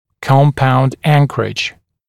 [‘kɔmpaund ‘æŋkərɪʤ][‘компаунд ‘энкэридж]комплексная анкеровка